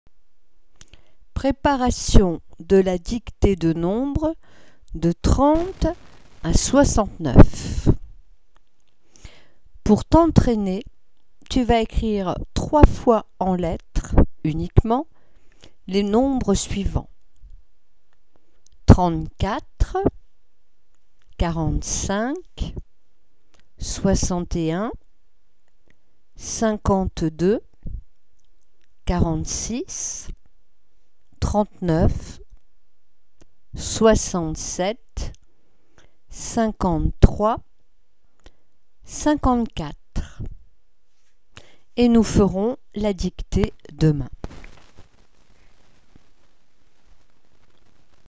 Dictée-de-nombres-de-30-à-69.mp3